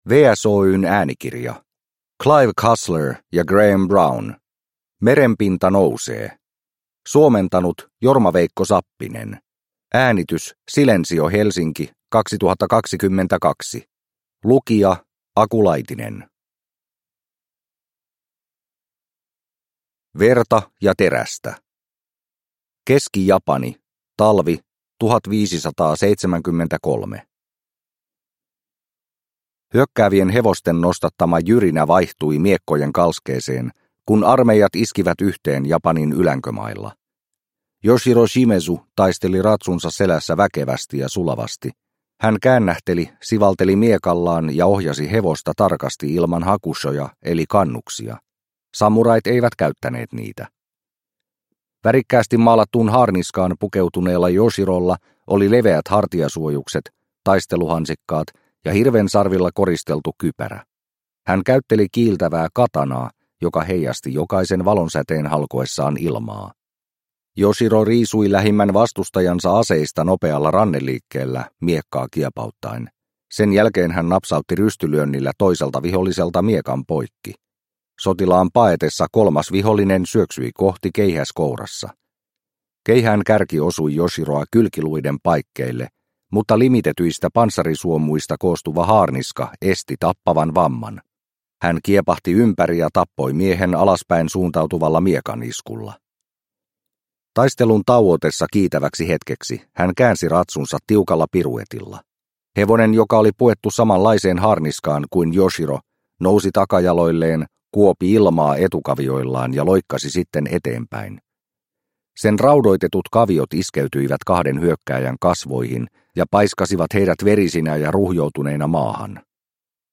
Merenpinta nousee – Ljudbok – Laddas ner